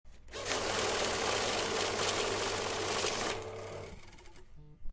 Maquina contando billetes: Efectos de sonido negocios
Este efecto de sonido ha sido grabado para capturar la precisión del sonido de una máquina contando billetes, proporcionando un sonido claro y distintivo que se integrará perfectamente en tus proyectos.
Tipo: sound_effect
Maquina contando billetes.mp3